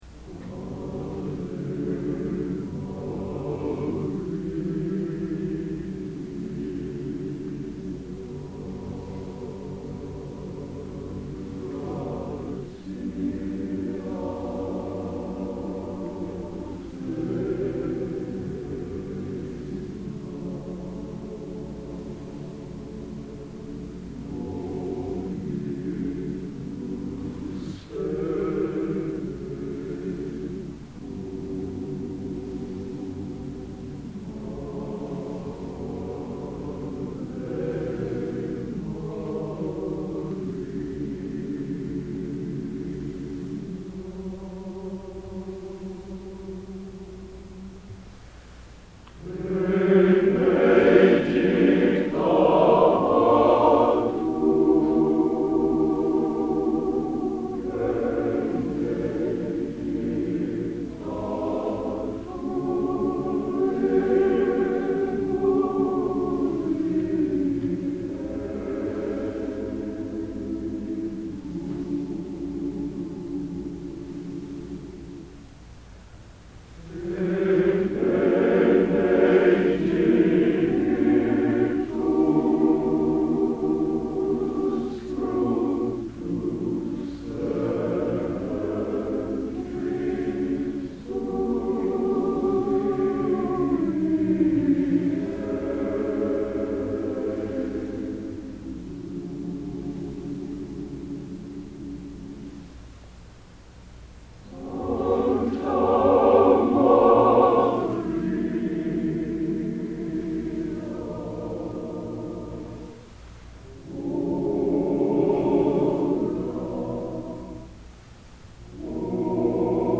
Location: West Lafayette, Indiana
Genre: Sacred | Type: End of Season